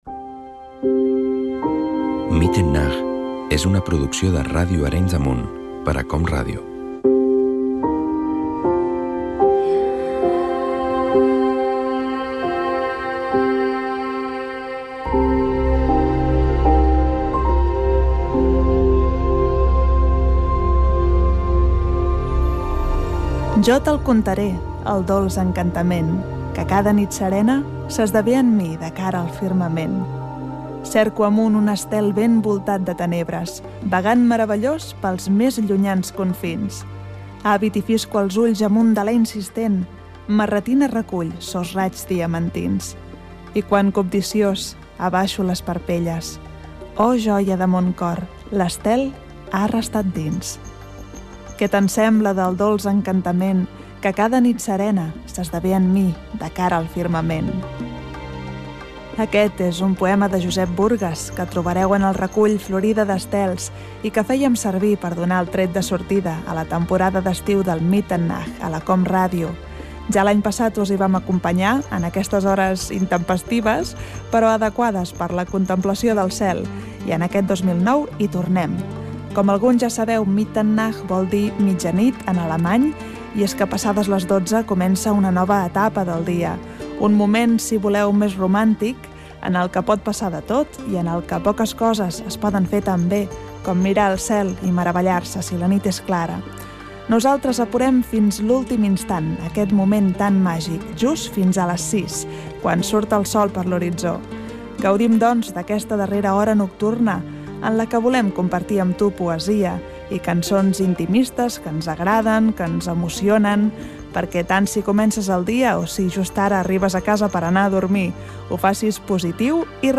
Identificació del programa i lectura d'una poesia amb música "chill out".